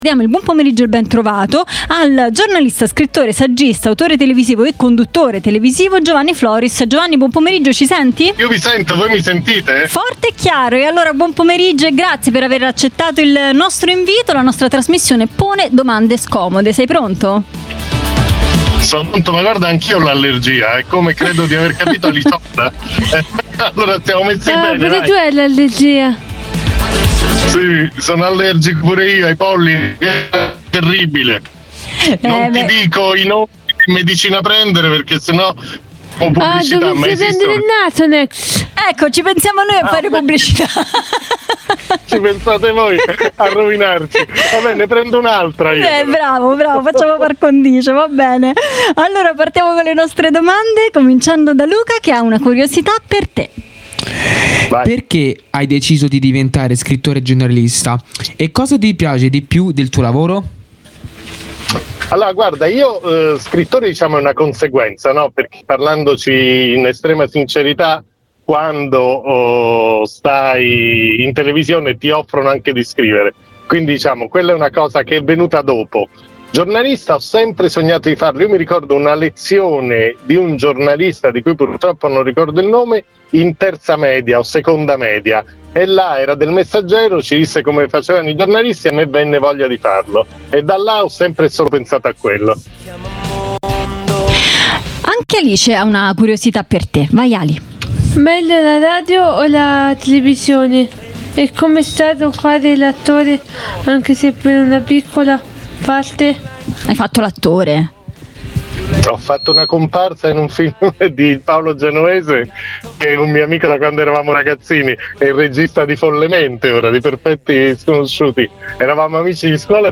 Jolly Roger - Puntata 22 - Intervista a Giovanni Floris